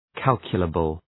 Προφορά
{‘kælkjələbəl}
calculable.mp3